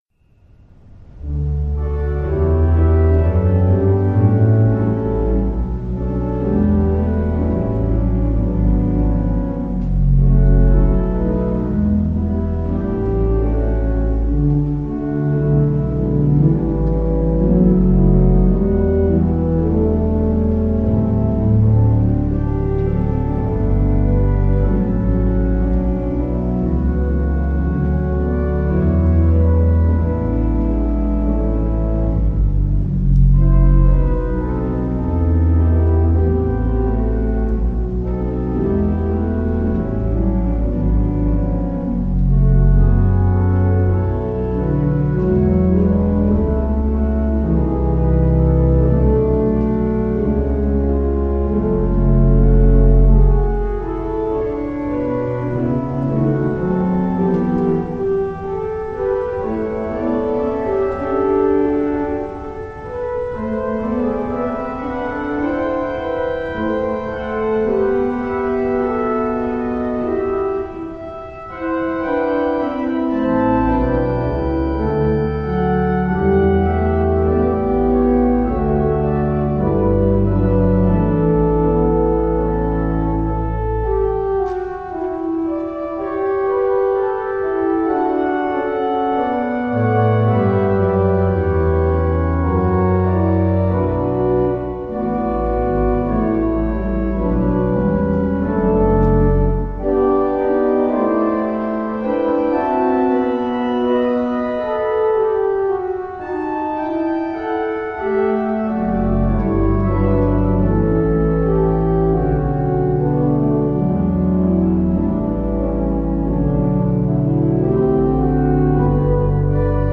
All other tracks are at St Anns Manchester.